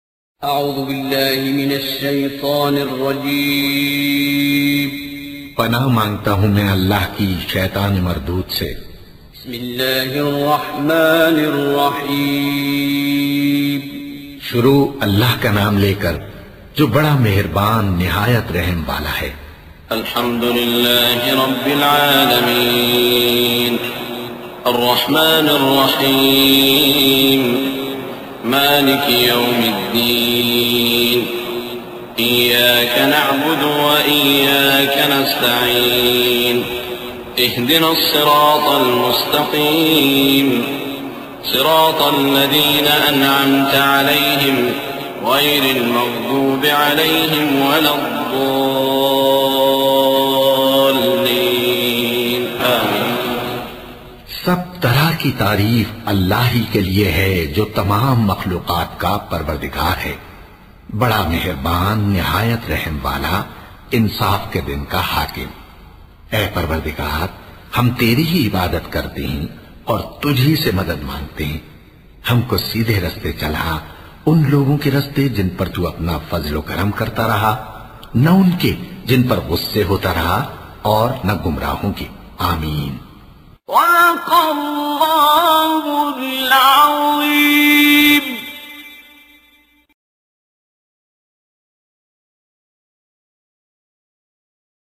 Surah Fatiha is Ist chapter of Quran Kareem. Listen audio and download mp3 free recitation/ Tilawat of Surah Fatiha with urdu Translation.